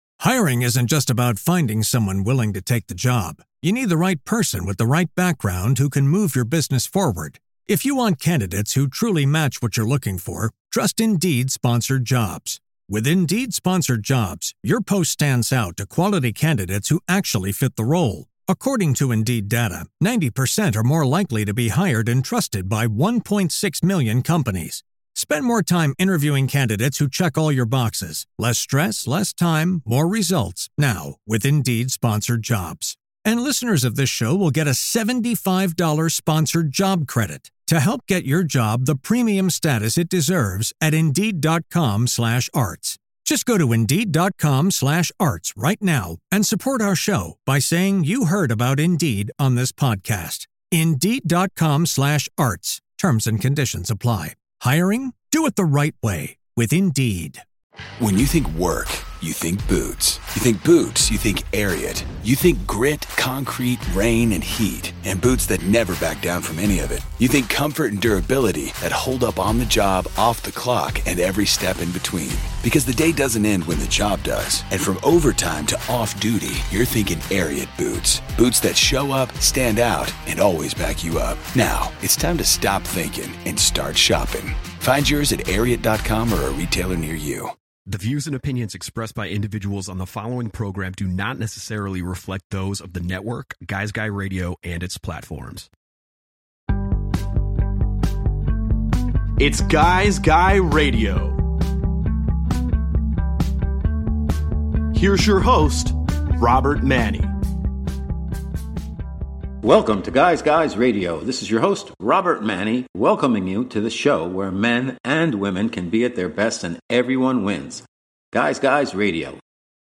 Guy’s Guy Radio features interviews and in-depth conversations with thought leaders across the worlds of relationships and modern masculinity, spirituality, health, wellness and diet, business, and much more.